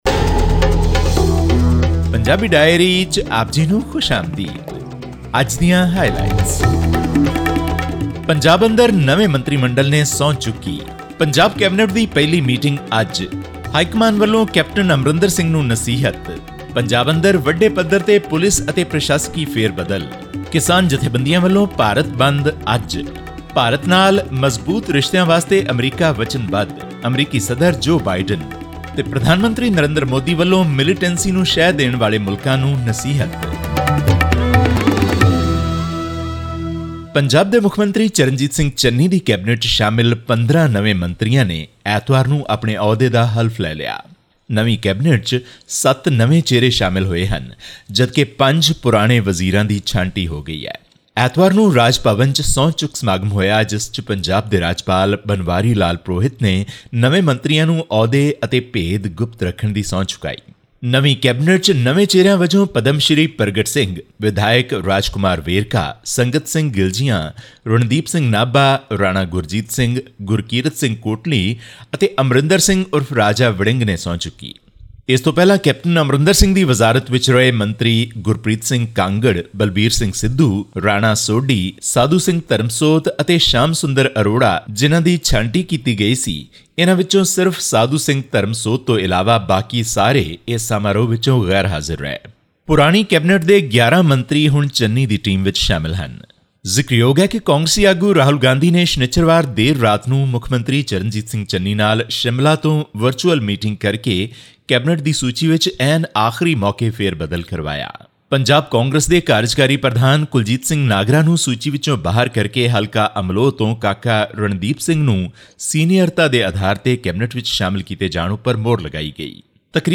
On September 26, 15 Cabinet Ministers took oath as new ministers in Punjab at the Raj Bhavan in Chandigarh, a week after Charanjit Singh Channi was announced as the new chief minister of the poll-bound state. This and more in our weekly news update from Punjab.